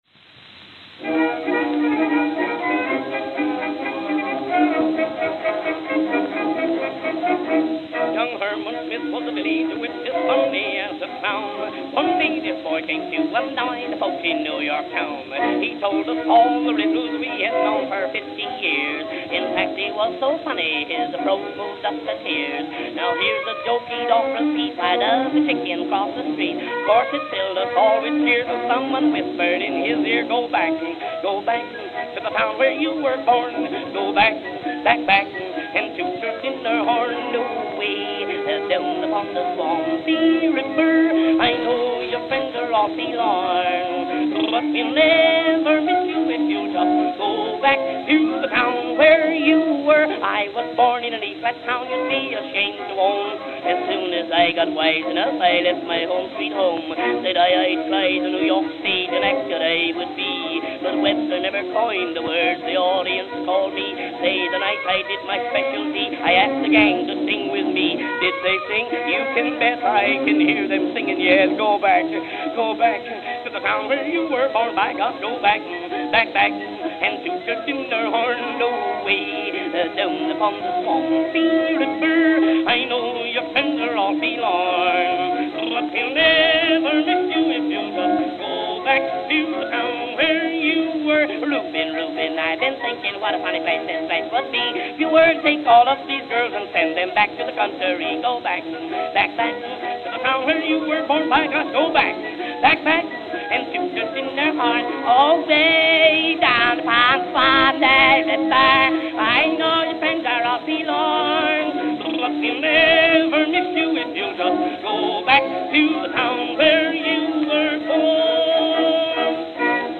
Camden, New Jersey Camden, New Jersey